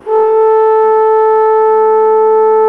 TENORHRN A 2.wav